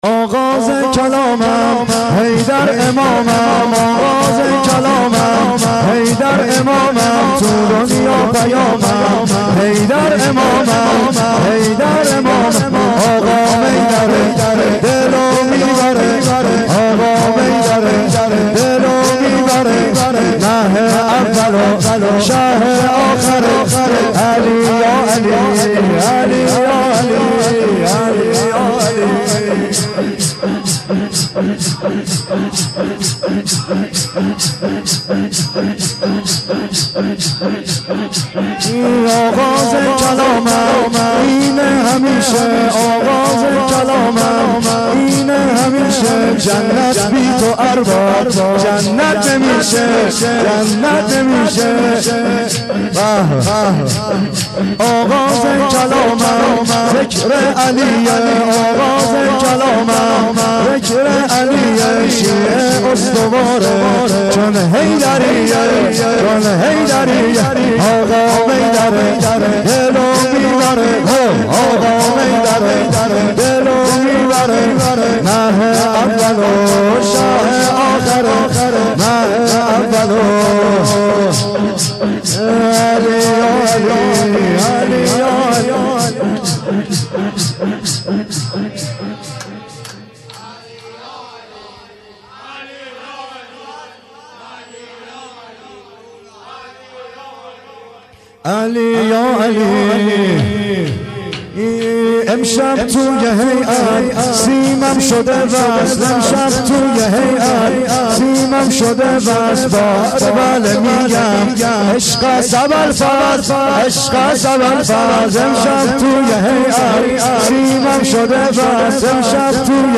آغاز کلامم ، حیدر امامم (سینه زنی/ شور